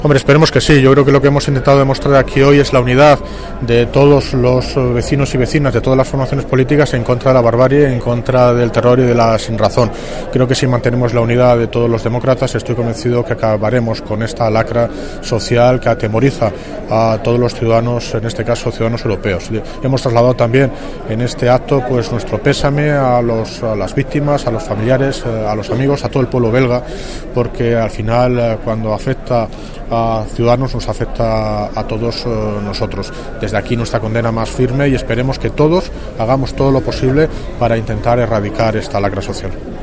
Audio - David Lucas (Alclade de Móstoles) Minuto de silencio por Bruselas